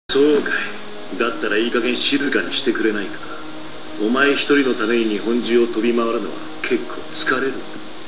Quotes and Voice Files
His seiyuu was Hirotaka Suzuoki.